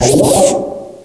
pokeemerald / sound / direct_sound_samples / cries / accelgor.aif